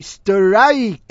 l_stirrrrrike.wav